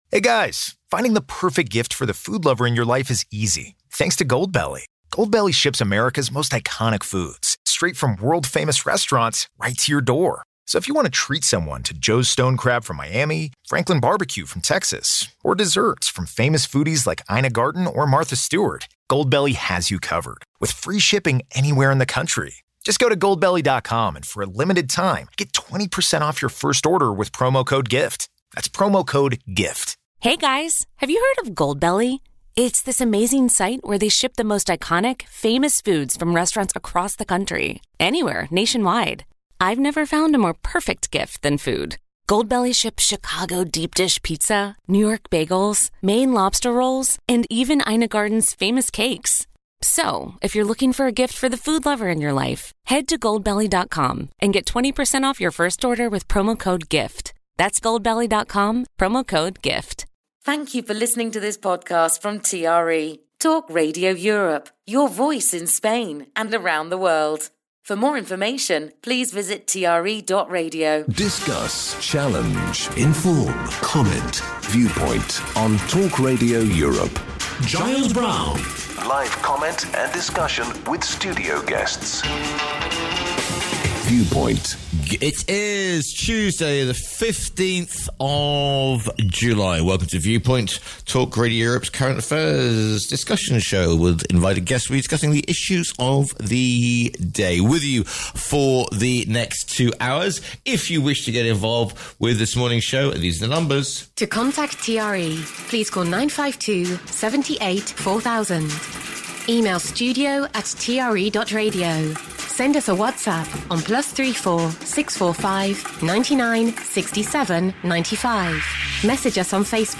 his panel of guests